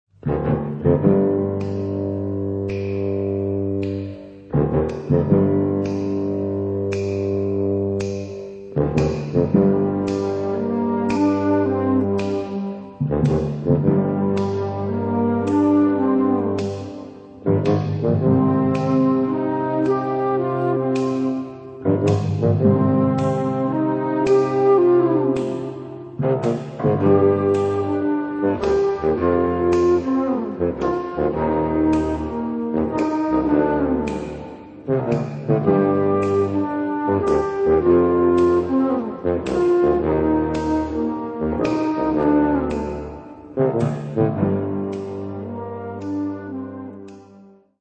22 groovy tv & movie themes from 1969-1984